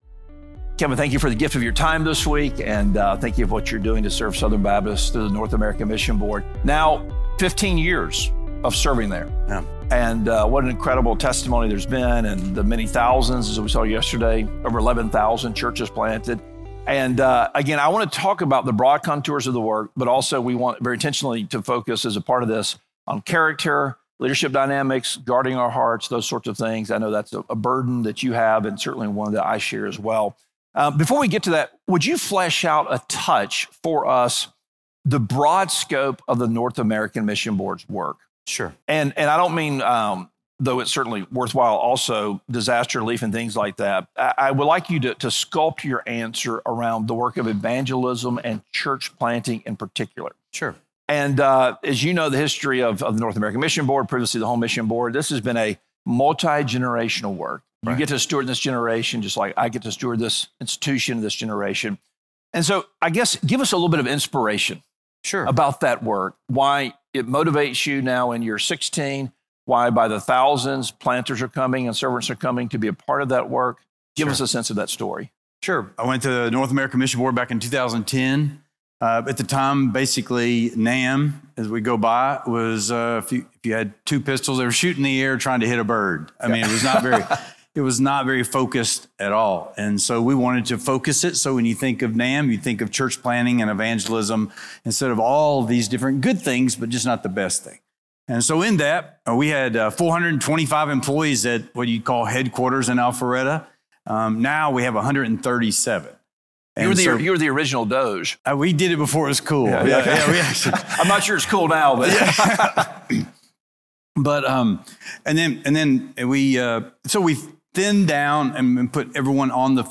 Special Q&A